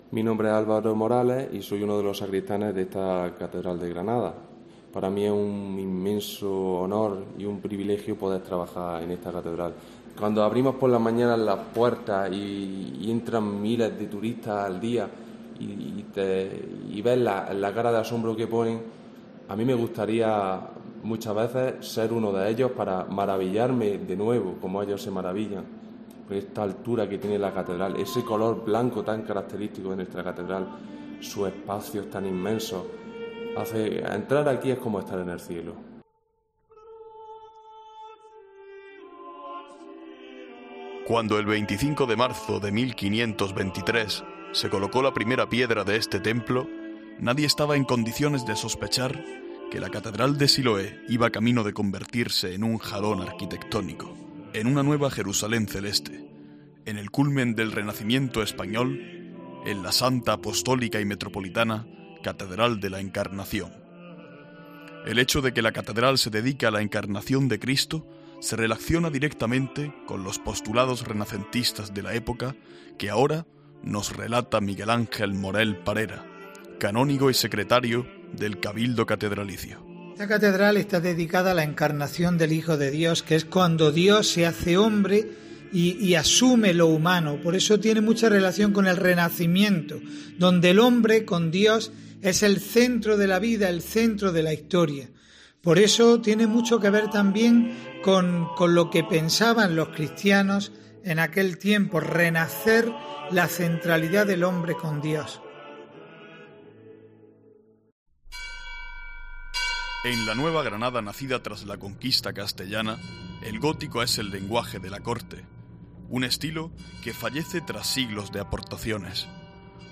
No te pierdas este reportaje original de COPE sobre un aniversario...